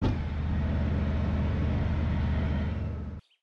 На этой странице представлены звуки работы РСЗО \
Грохот реактивной системы Залпоград